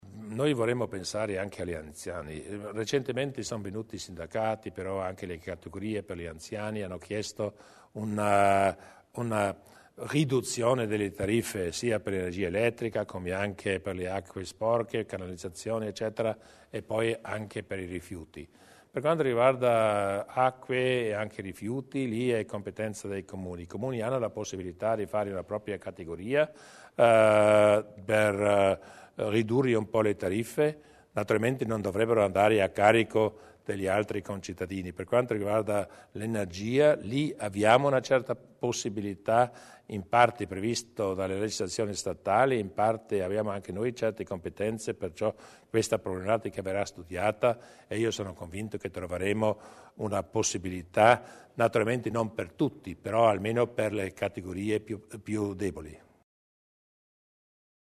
Il Presidente Durnwalder illustra i progetti a favore degli anziani
Di seguito alcune delle decisioni assunte dalla Giunta provinciale nella seduta di oggi (25 gennaio) e illustrate dal presidente Luis Durnwalder nella successiva conferenza stampa.